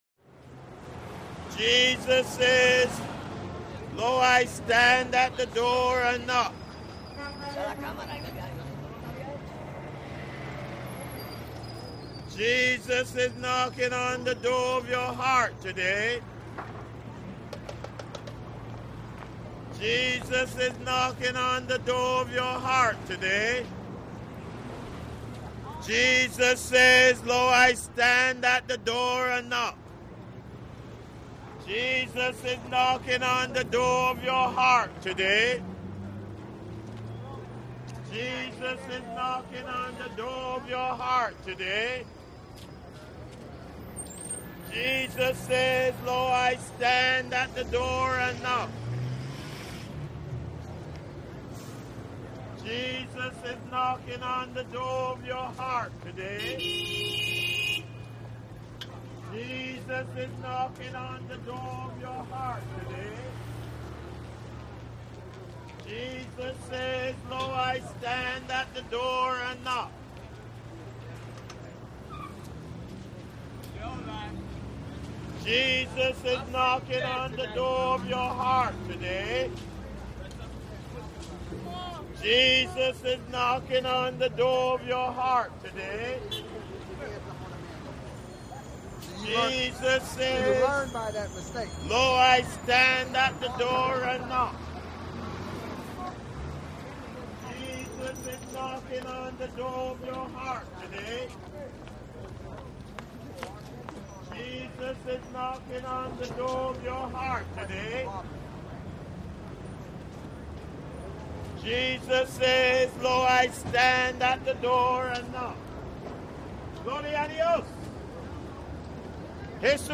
MaleStreetPreacher CT021301
Male Street Preacher Repeats, Jesus Is Knocking On The Door Of Your Heart Today... Etc. Close To Medium With Some Movement. Heavy Street Ambience; Close Walla Bys, Movement, Traffic Medium With